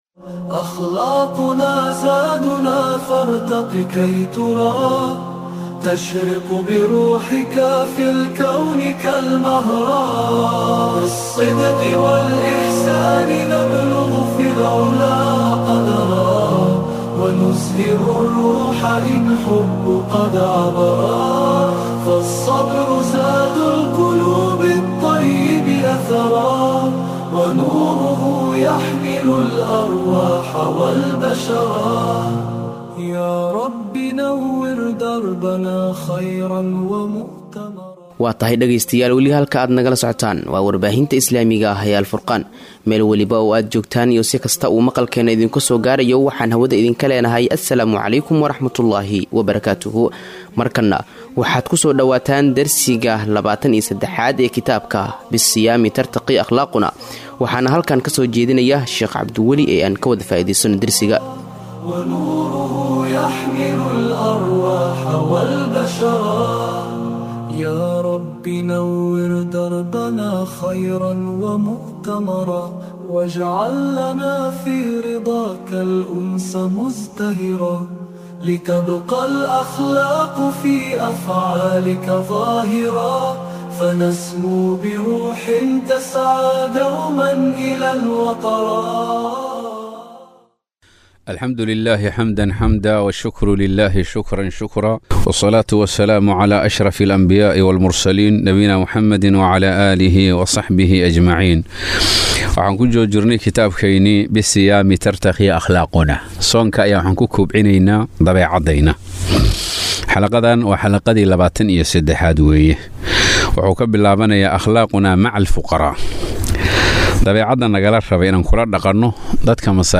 Dersiga 23aad
Inta lagu guda jiro bishaan waxaan dhageystayaasheenna ugu tala galnay duruus Ramadaani ah, waxaana duruustaasi kamid ah kitaabka Soonka iyo Akhlaaqda